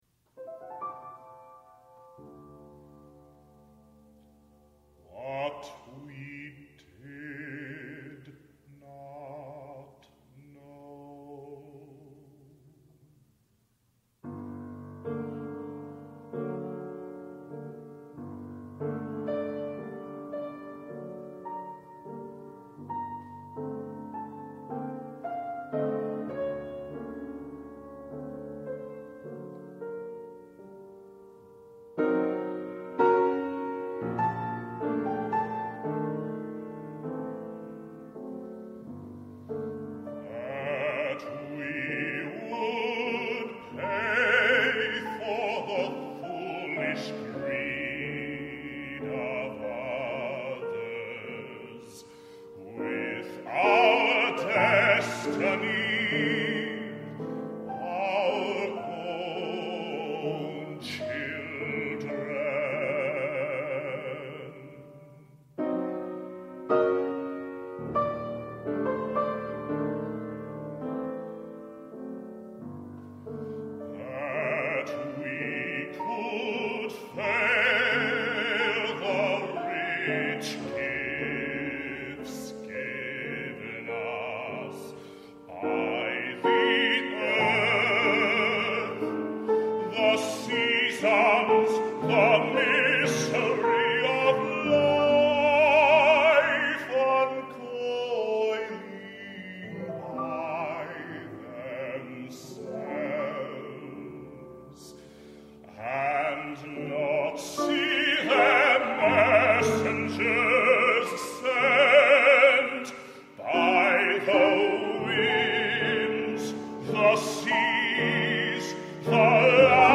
Baritone , Individual Art Songs